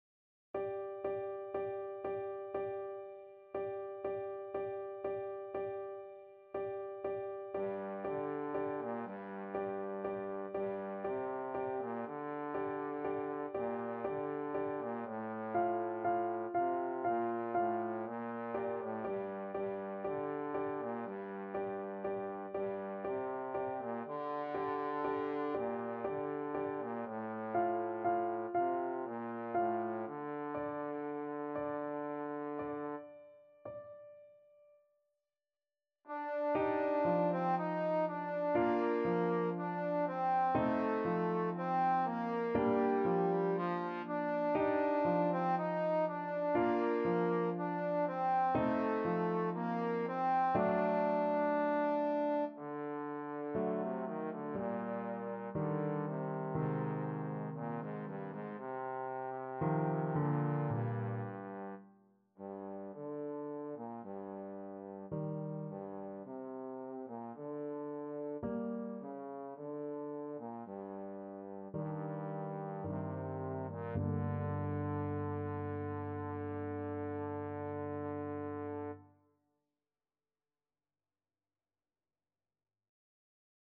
Trombone
G minor (Sounding Pitch) (View more G minor Music for Trombone )
3/4 (View more 3/4 Music)
Andante sostenuto =60
Classical (View more Classical Trombone Music)